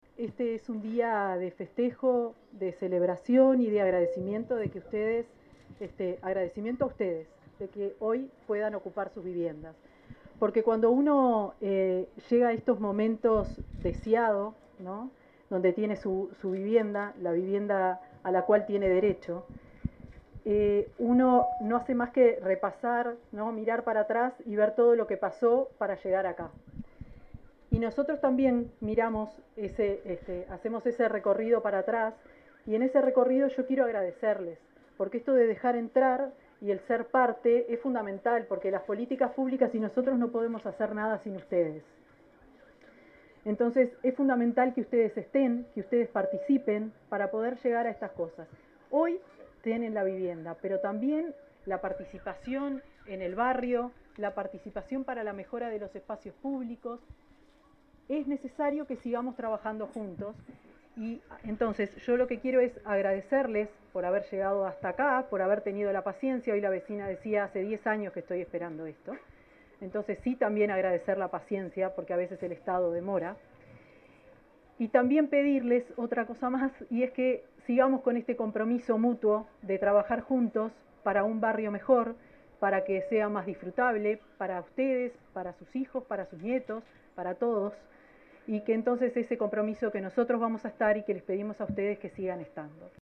Palabras de la ministra de Vivienda, Tamara Paseyro
La ministra de Vivienda, Tamara Paseyro, entregó 12 viviendas en la ciudad de Pando, departamento de Canelones.